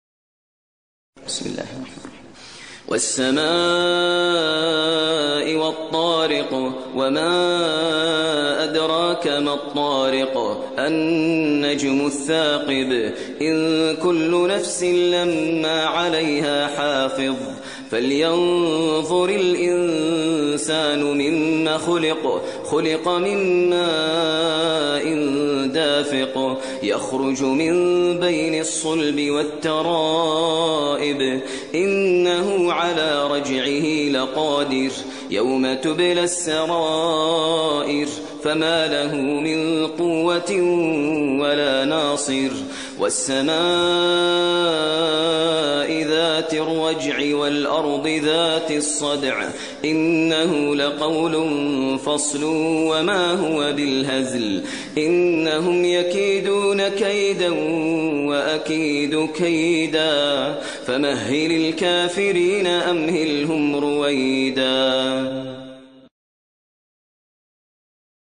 ترتیل سوره طارق با صدای ماهر المعیقلی
086-Maher-Al-Muaiqly-Surah-At-Tariq.mp3